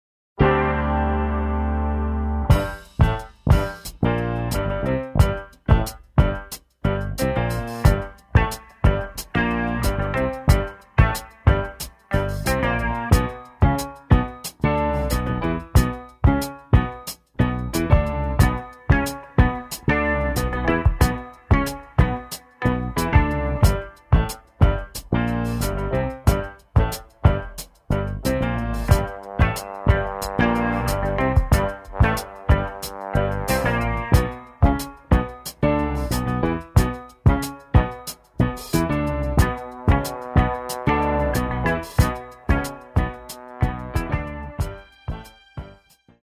in the idioms of funk, jazz and R&B